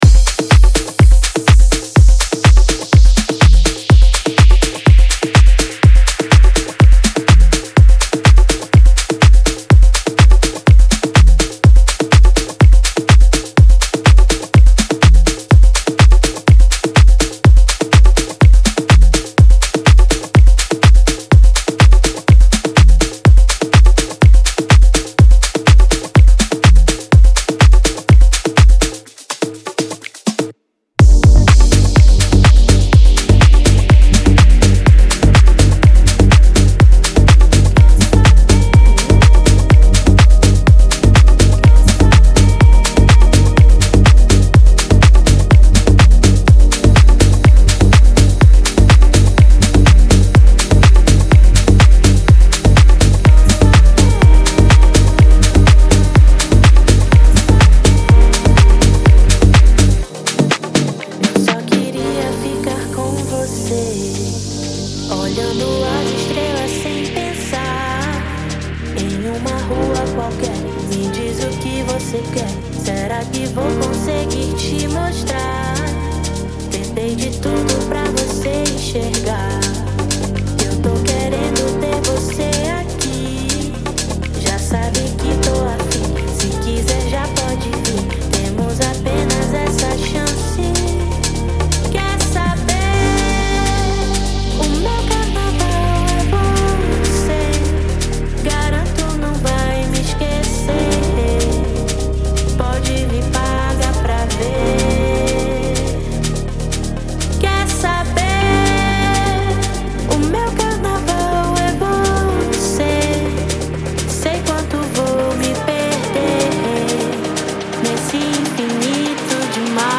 EstiloHouse